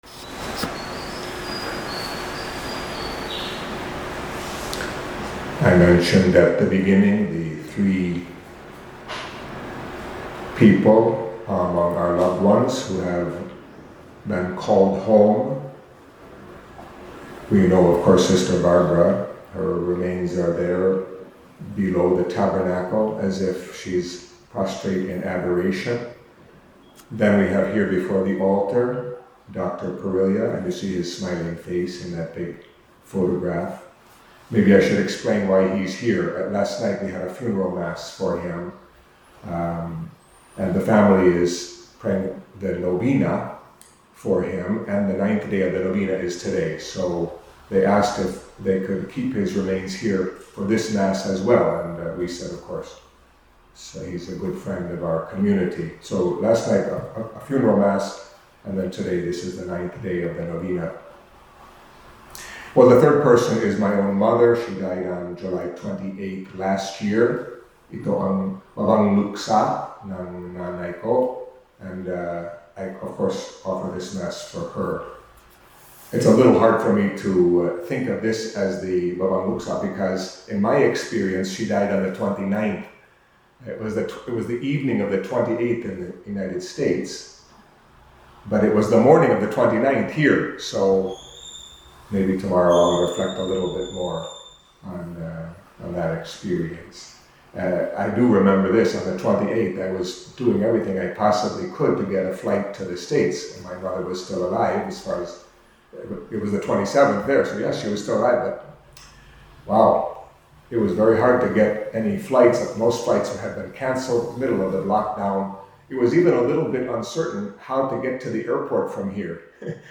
Catholic Mass homily for Wednesday of the 17th Week in Ordinary Time